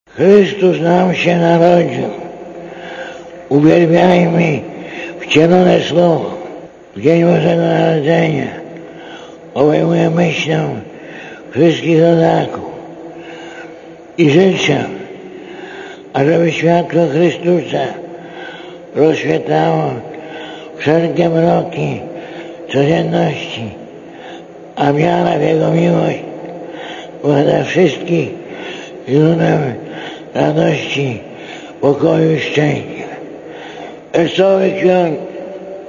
Po odczytaniu orędzia papież przystąpił do składania życzeń w kilkudziesięciu językach.
Na placu świętego Piotra zgromadziło się kilkadziesiąt tysięcy osób.